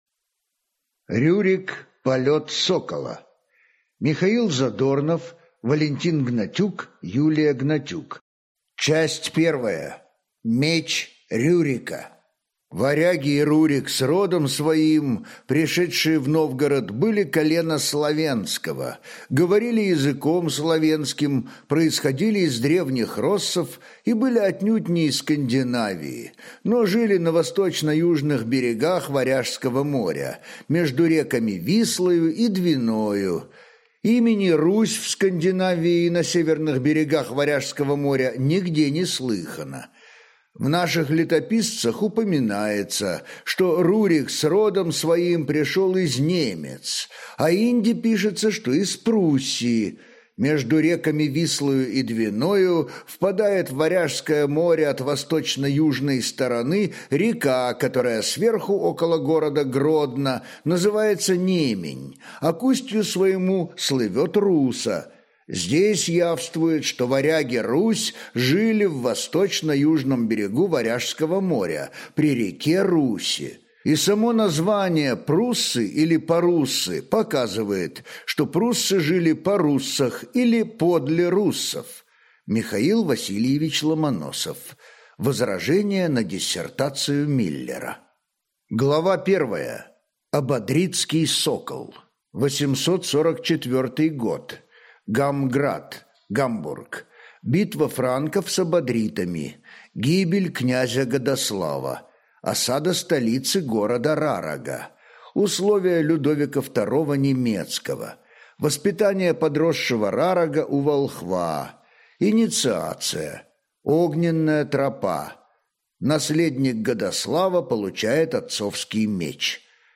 Аудиокнига Рюрик. Полёт сокола | Библиотека аудиокниг